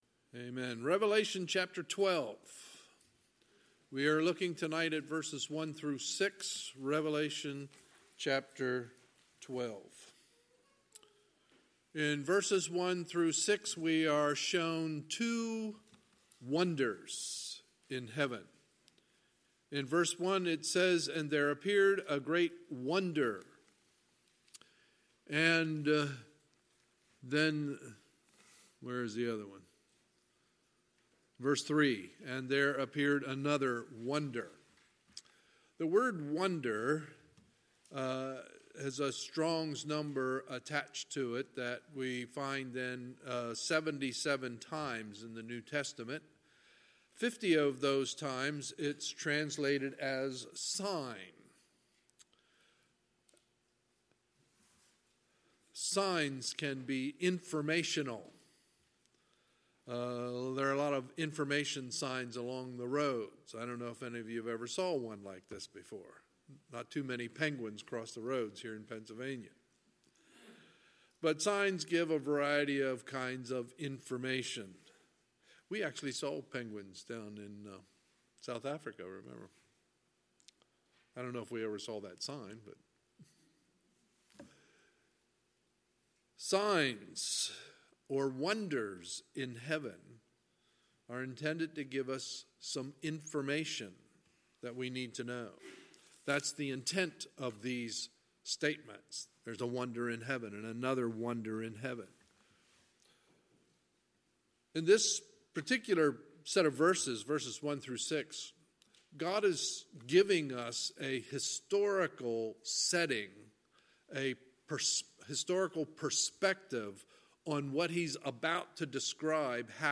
Sunday, March 10, 2019 – Sunday Evening Service
Sermons